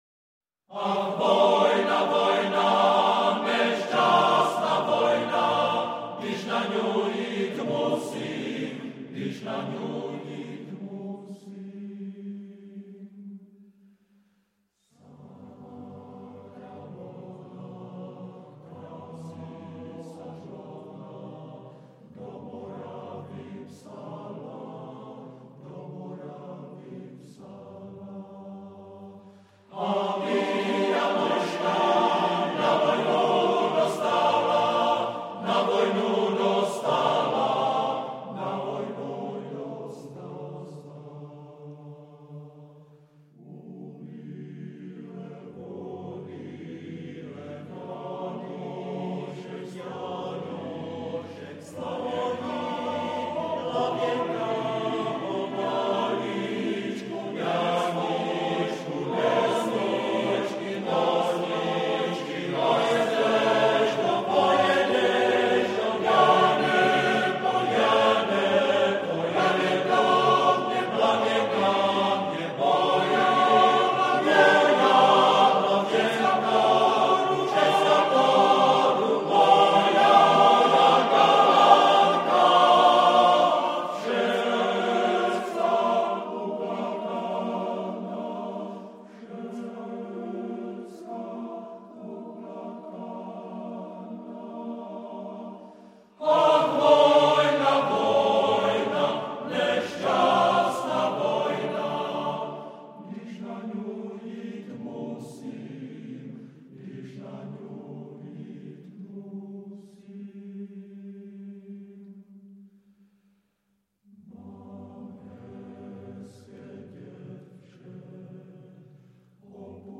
FF:VH_15b Collegium musicum - mužský sbor